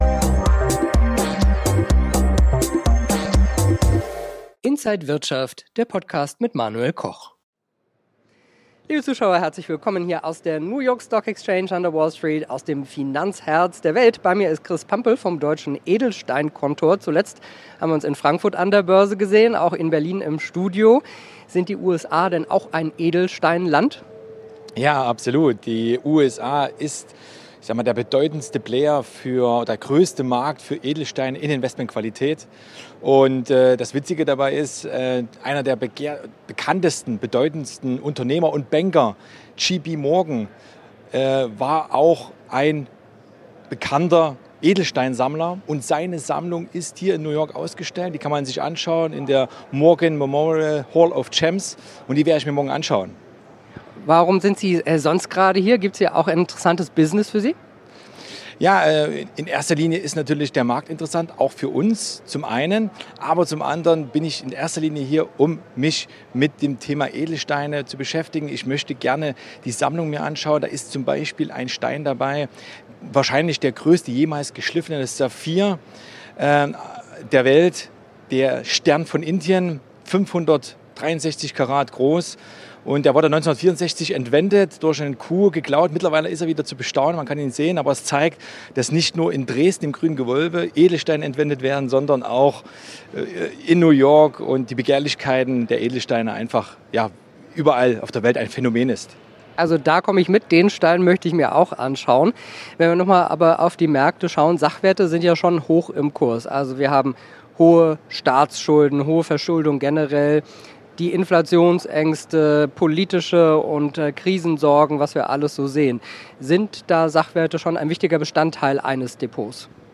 Alle Infos im Interview von Inside
an der New York Stock Exchange